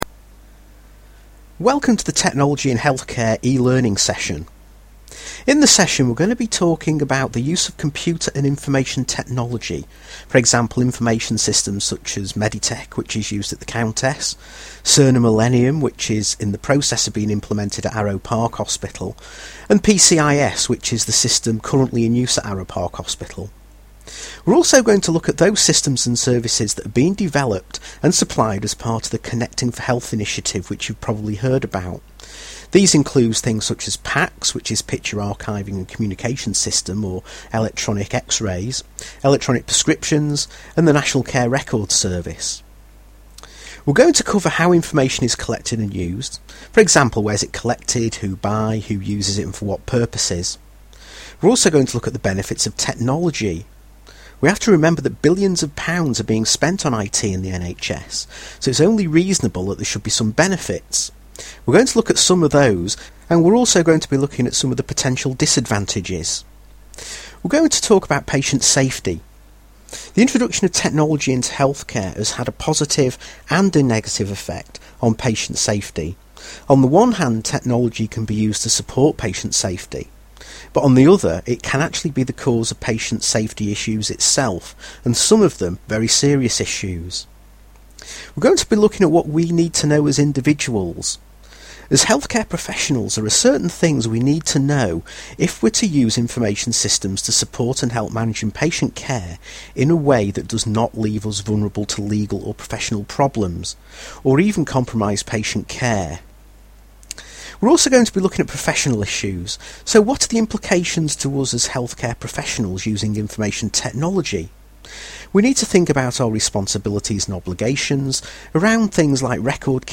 An eLecture covering the use of computer technology by healthcare staff. Covers legislation, patient safety, professional and organisational aspects as well as describing the latest developments.